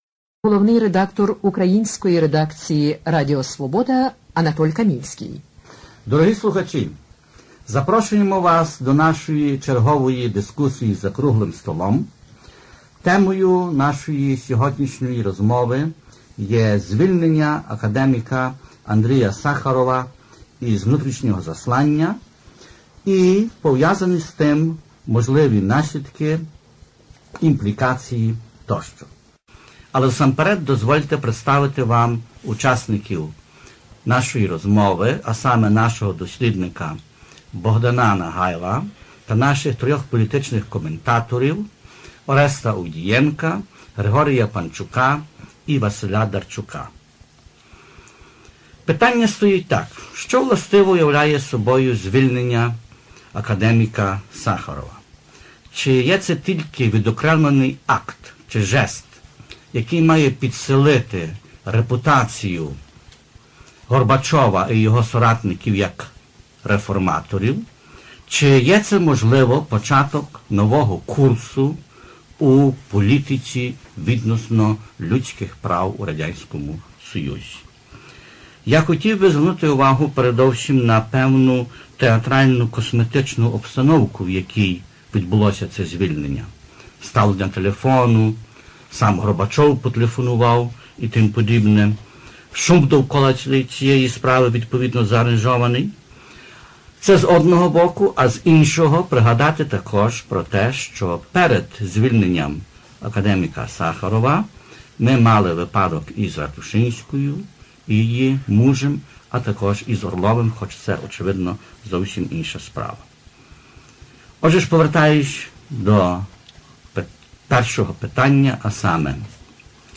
Чому звільнили Сахарова? Обговорення Радіо Свобода (грудень, 1986)